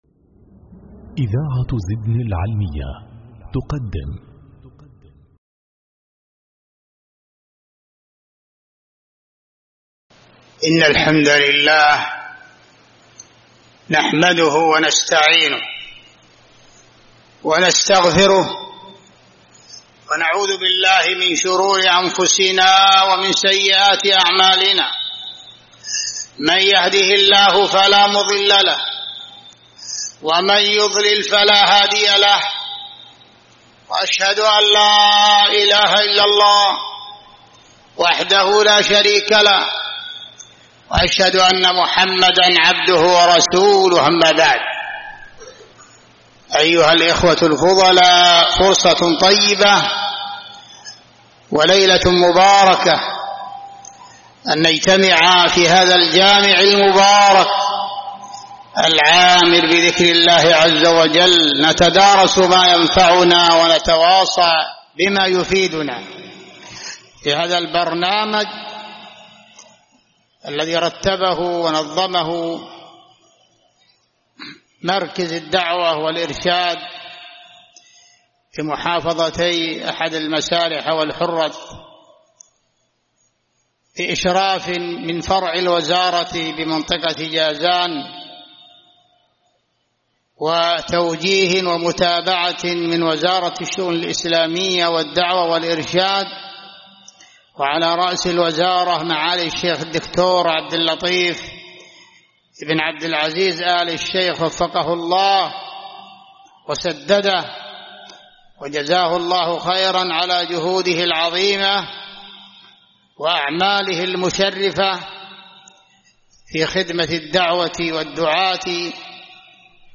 شرح حسن الخلق جامع أبوبكر الصديق بإسكان رمادة